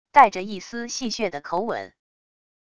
带着一丝戏谑的口吻wav音频